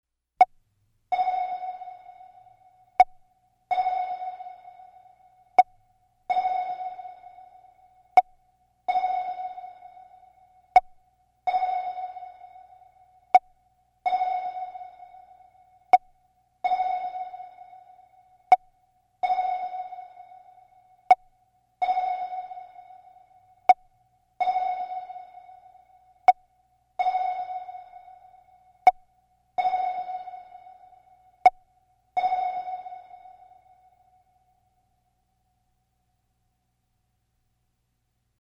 Звук гидролокатора подводной лодки